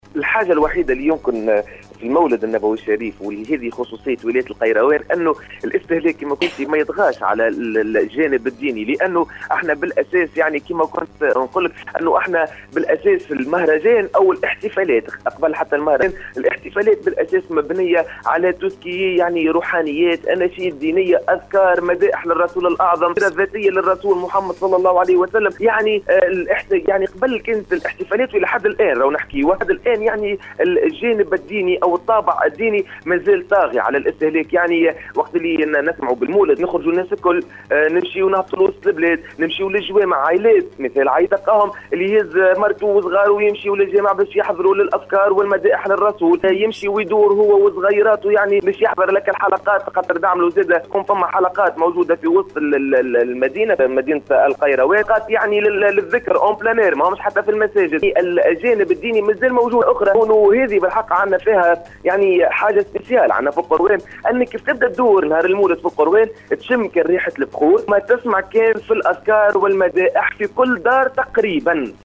الصحفي